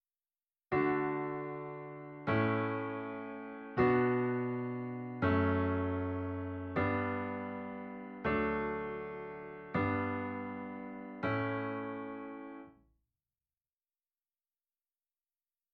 My goal for this website is to present information that is practical in nature, so as luck would have it, I was working on a melody last night based on the ‘Avril Lavigne’ progression, and came up with this: